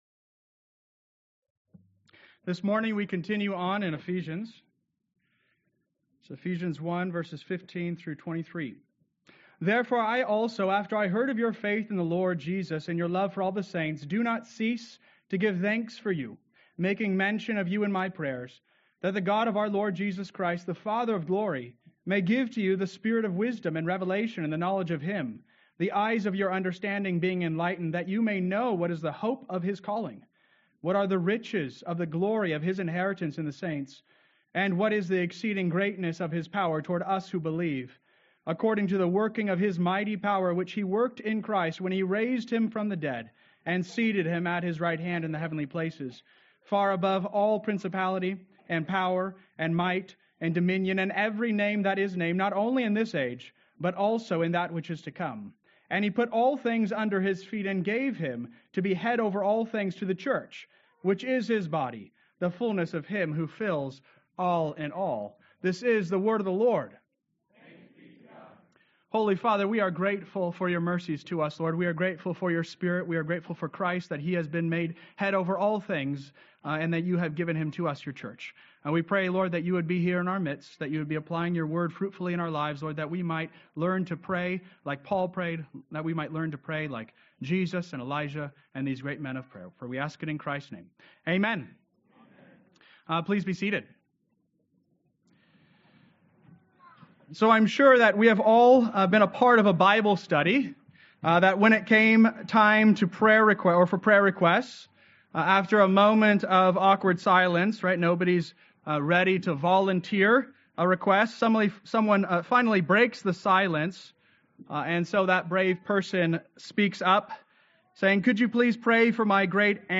Sermon Outline: Ephesians Part III – Thanksgiving & Prayer